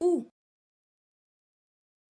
La letra U tiene siempre el mismo sonido y equivale en francés al sonido: " ou ".